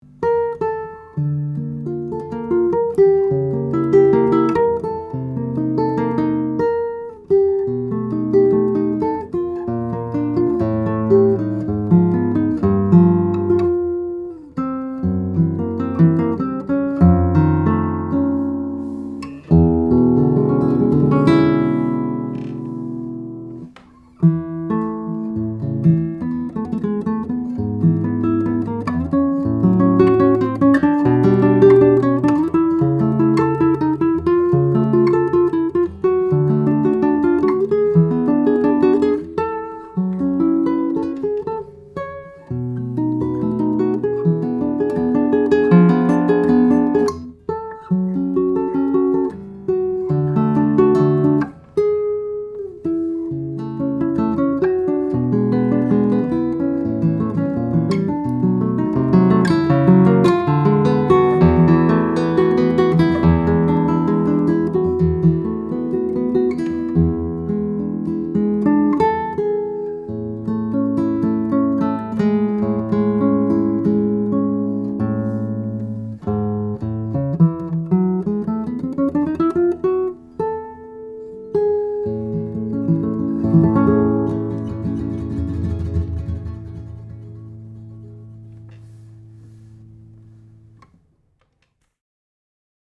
2017 Gutmeier "Santos Hernandez" Classical, Indian Rosewood/European Spruce - Dream Guitars
This beauty is still fresh off the bench from 2017, and has impressive sonority throughout the bass register, with clean, smooth trebles.